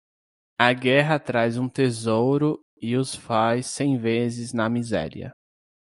Pronounced as (IPA)
/miˈzɛ.ɾi.ɐ/